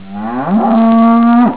Cow 1
COW_1.wav